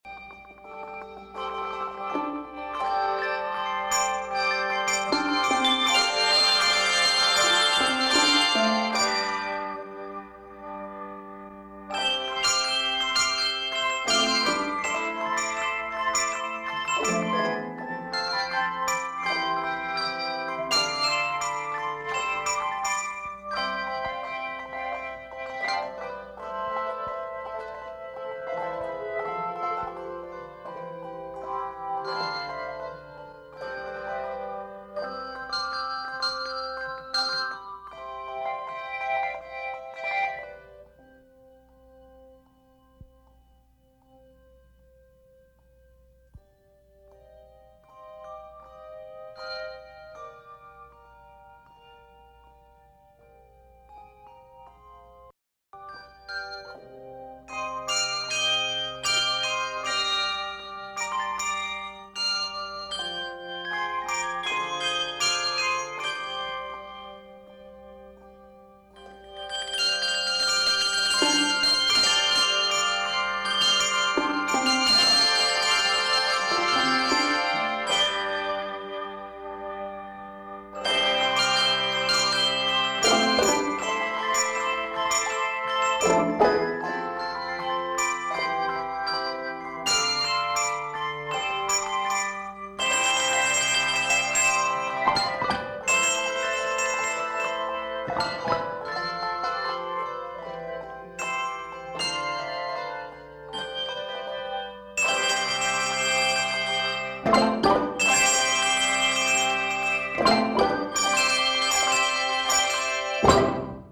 Review: A festive piece in ABA form.